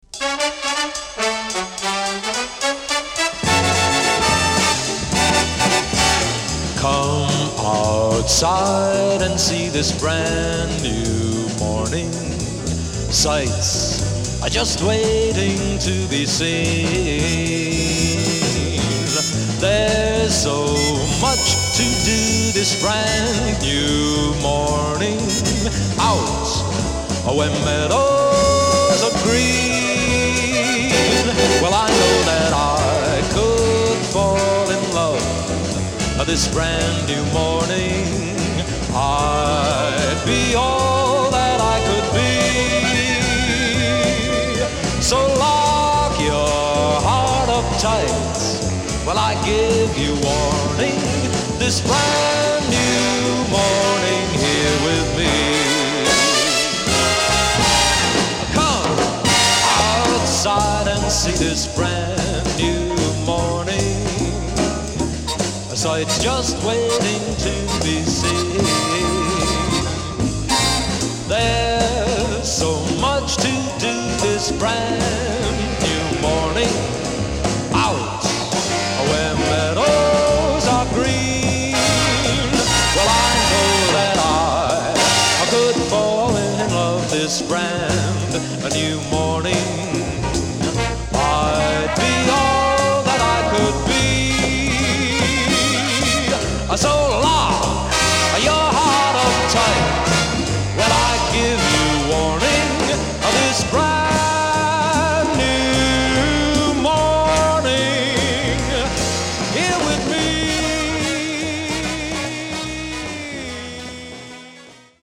ダイナミックなオーケストレーション